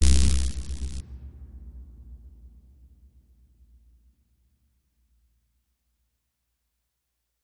Descarga de Sonidos mp3 Gratis: sintetizador 18.
bajo_22.mp3